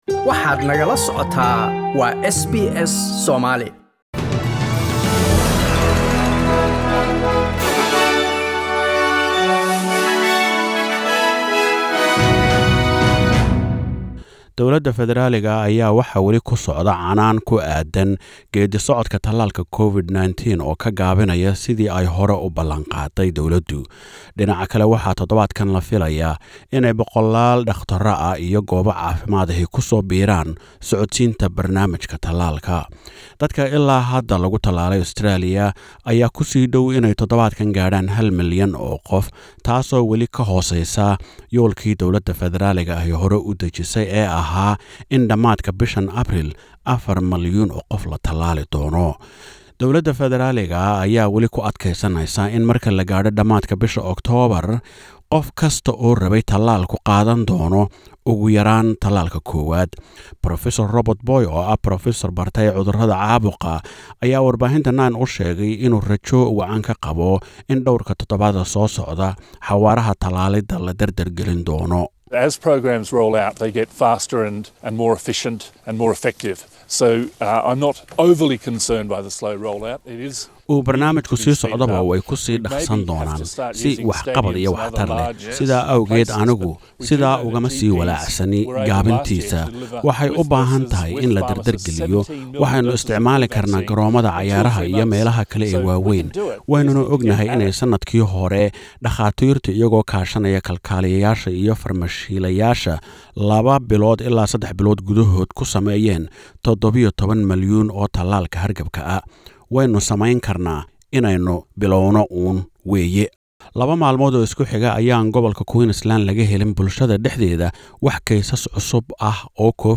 Warka Isniinta 5ta Apriil 2021